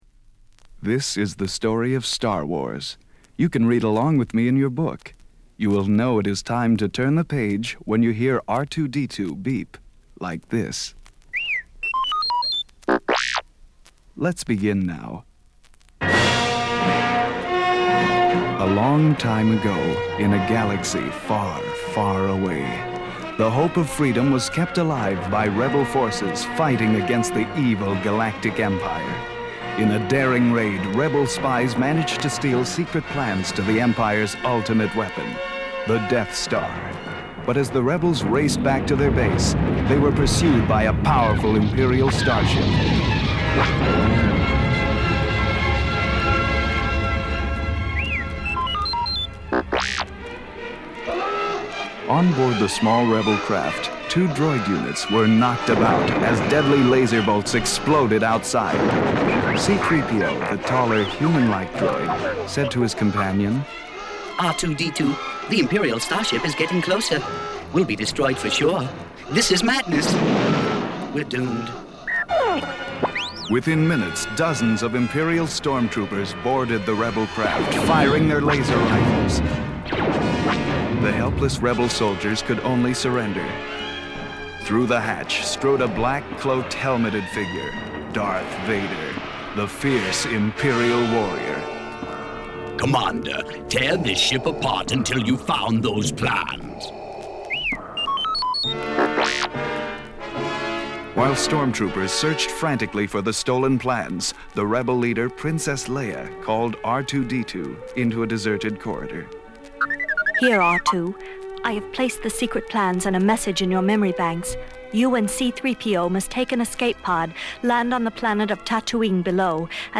The book came with a vinyl record that was meant to be played along with the book; I’ve included its audio below in MP3 format:
The book came with a vinyl record that was meant to be played along with the book; I’ve included its audio below in MP3 format: The Story of Star Wars , Part 1 The Story of Star Wars , Part 2 The voice work on the record is terribly off – whoever’s playing Darth Vader sounds more like Count Dracula, but you have to keep in mind the book was released in 1979, well before Star Wars had firmly established itself as part of the pop culture canon.